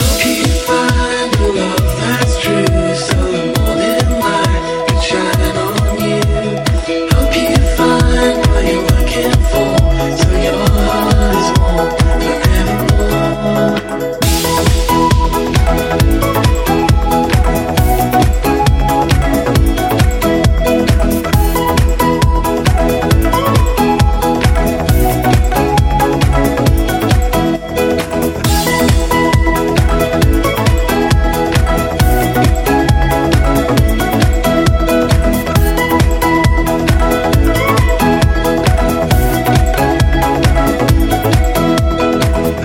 tropical
hit - remix
Genere: tropical house, remix